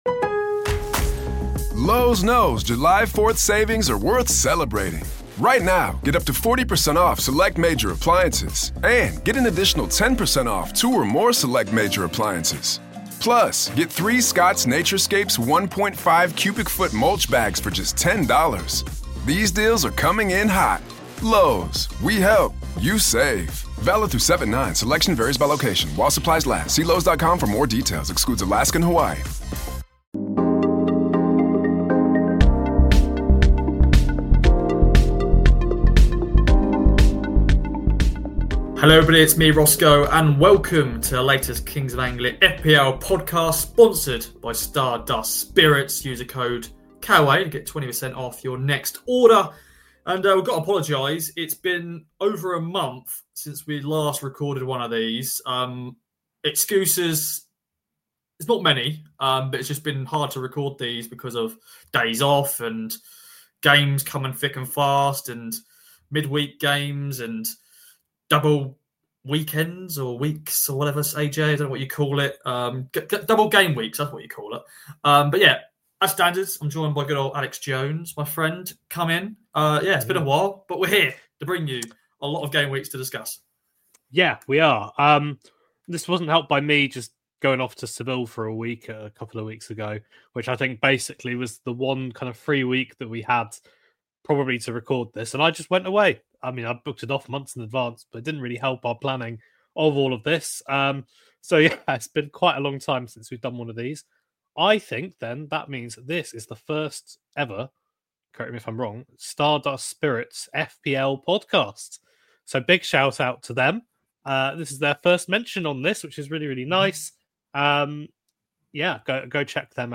There’s also a chat around the KOA FPL cup, while an unwelcome interruption from an Alexa causes a scene.